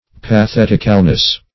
-- Pa*thet"ic*al*ness , n. [1913 Webster]